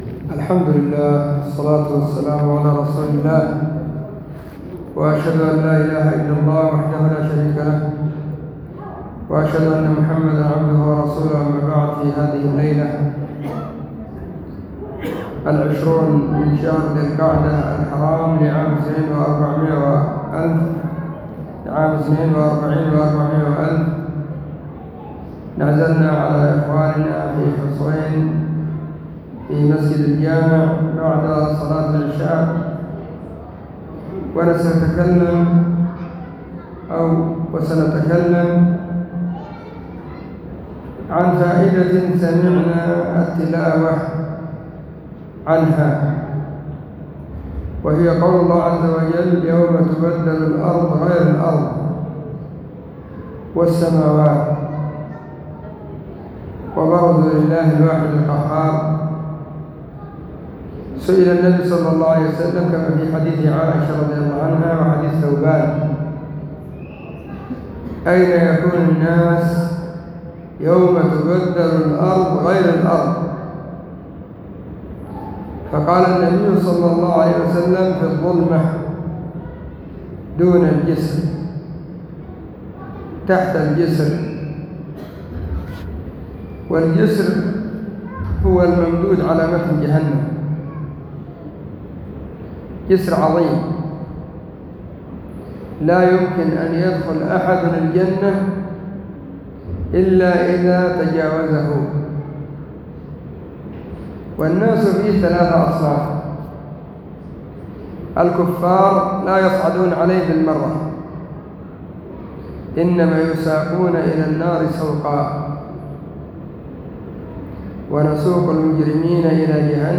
🎙كلمة بعنوان: *الصراط*